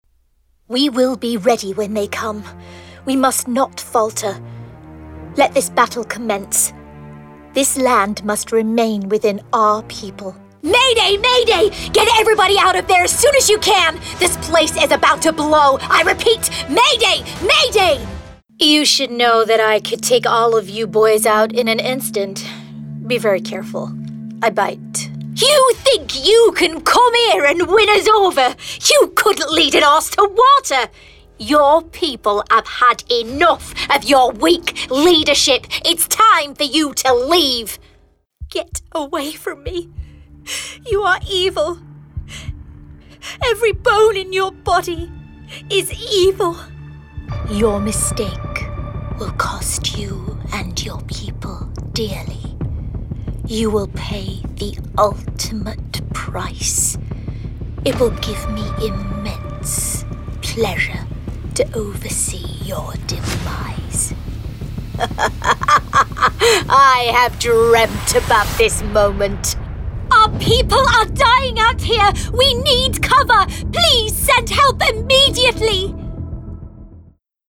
Gaming Showreel
Female
Estuary English
Friendly
Upbeat
Youthful
Bright